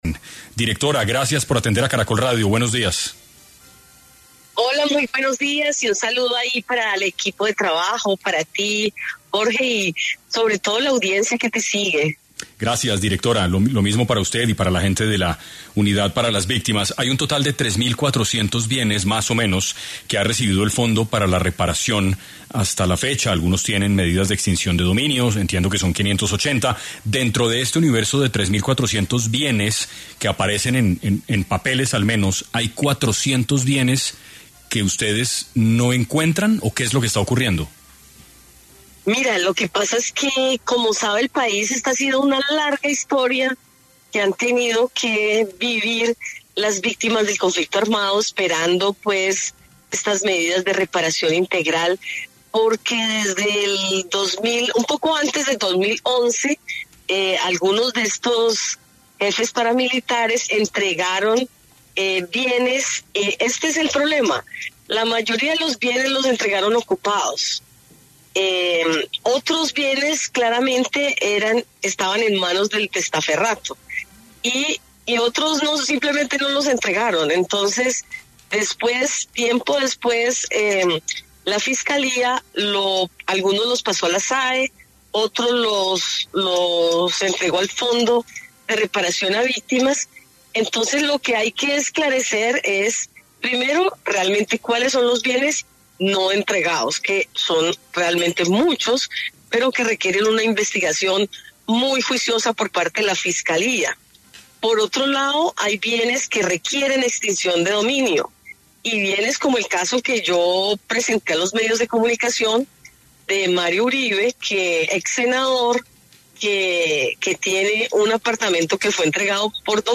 En ‘6AM’ de Caracol Radio estuvo Lilia Solano, directora de la Unidad para las Víctimas, quien aclaró qué sucedió con los 400 bienes destinados a la reparación de víctimas que no aparecen.